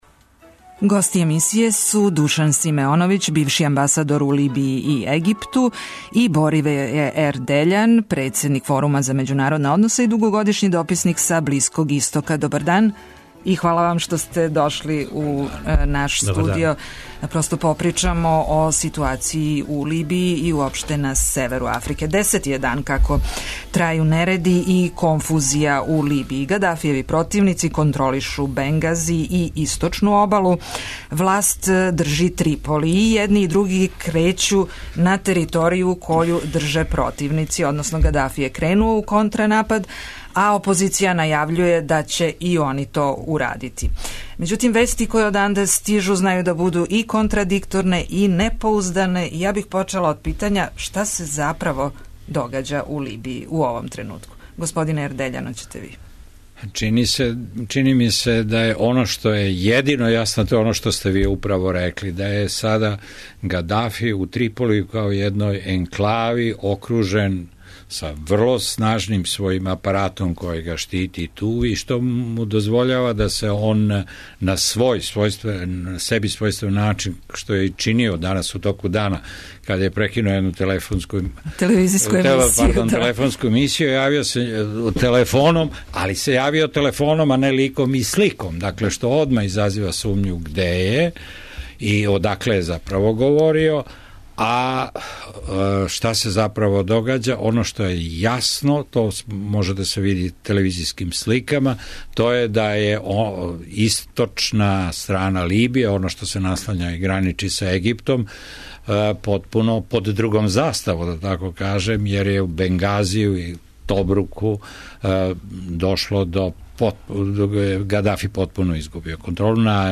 Биће то разговор о драматичном развоју догађаја у Либији као и анализа потреса који се ,од средине јануара, одвијају у земљама северне Африке и Блиског истока.